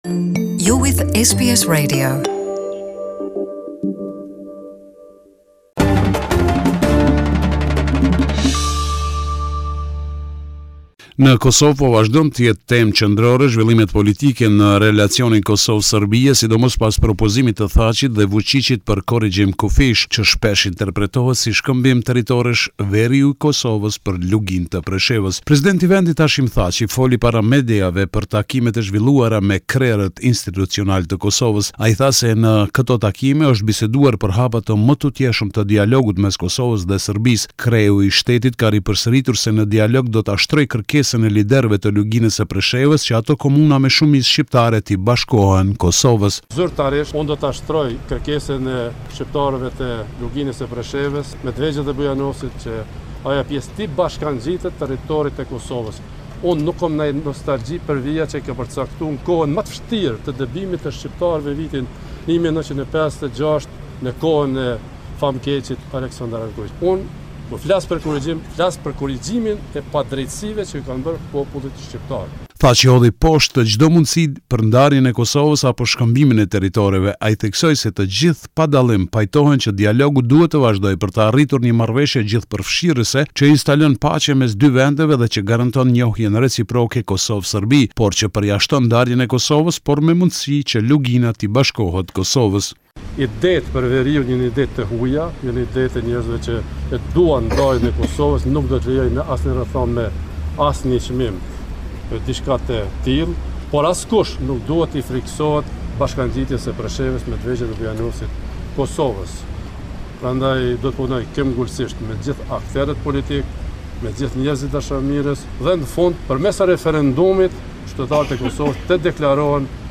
This is a report summarising the latest developments in news and current affairs in Kosova